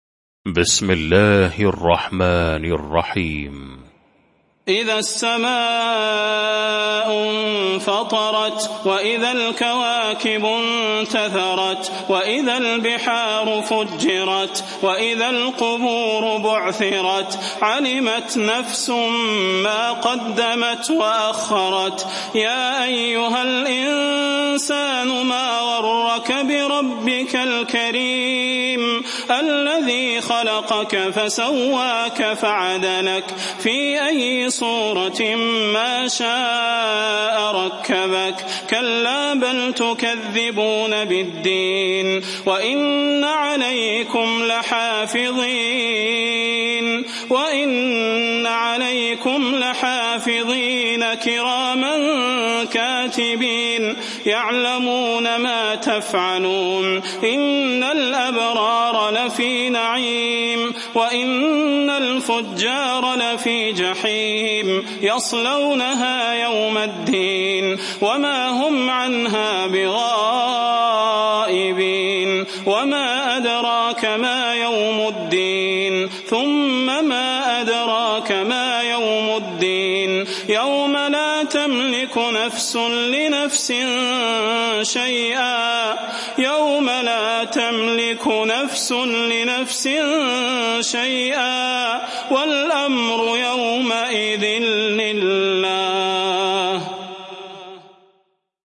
المكان: المسجد النبوي الشيخ: فضيلة الشيخ د. صلاح بن محمد البدير فضيلة الشيخ د. صلاح بن محمد البدير الانفطار